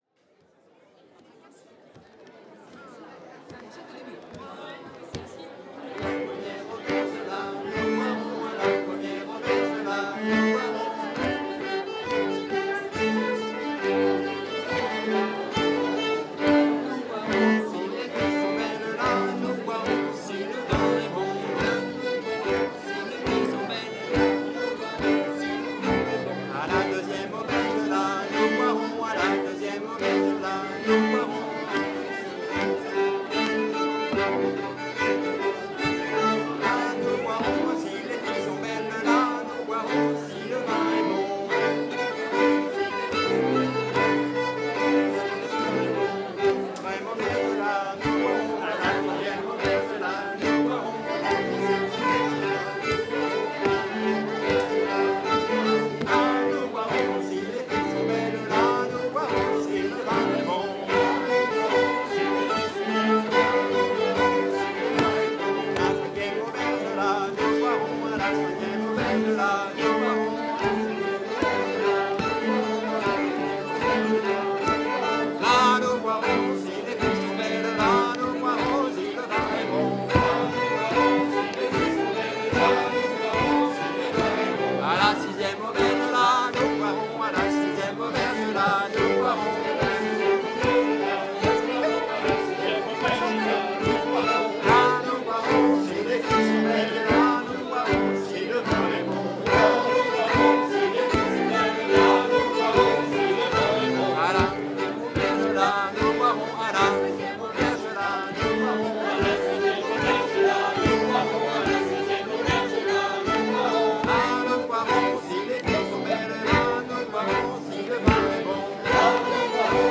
:mp3:2013:soiree_stagiaires
15_laride-violons.mp3